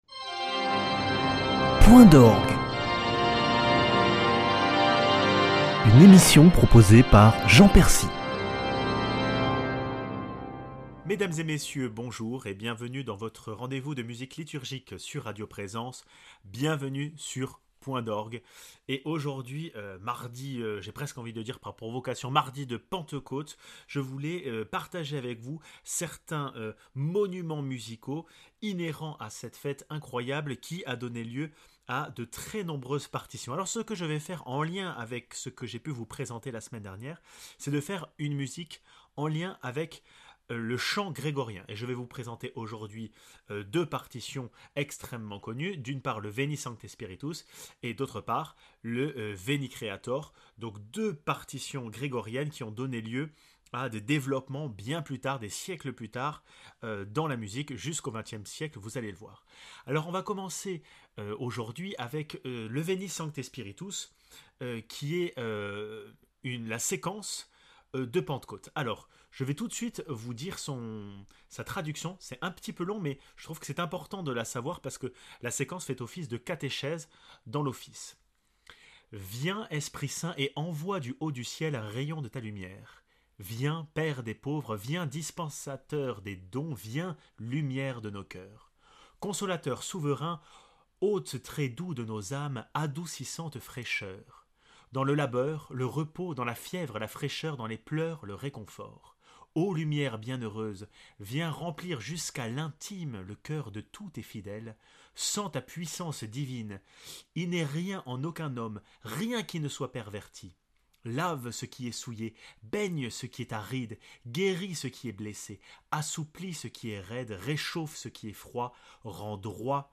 Par les thèmes du grégoriens, de nombreux artistes ont été inspirés afin de rendre hommage au Saint Esprit ! (Séquence Veni Sancte Spiritus de Revert, Veni creator de Grigny et de Duruflé)